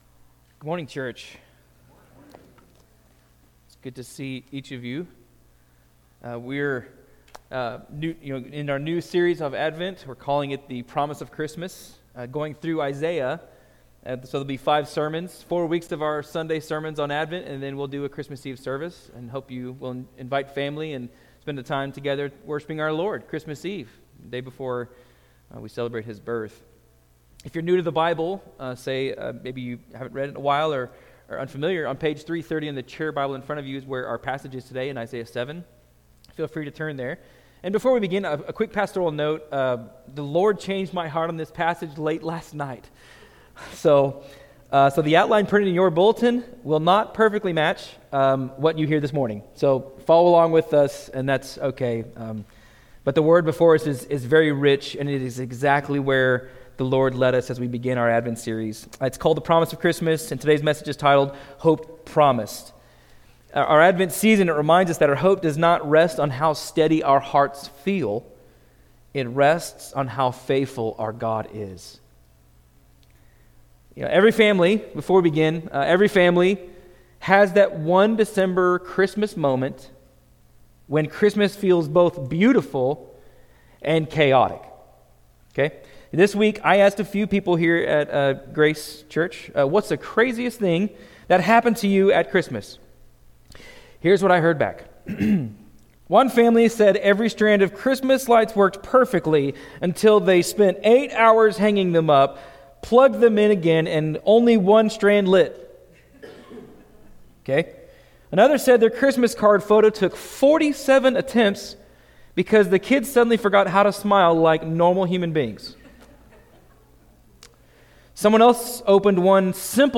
Grace Community Church Sermons
Sermons from Grace Community Church: Great Bend, KS